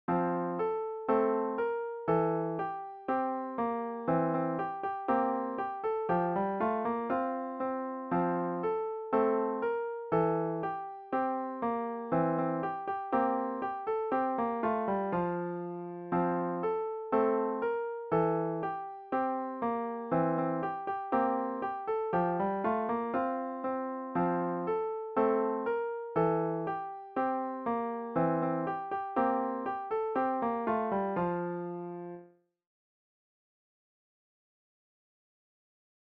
Hush Little Baby (Lullaby) | Free Easy Piano Sheet Music (Digital Print)
Hush Little Baby for Easy/Level 2 Piano Solo
hush-little-baby-piano.mp3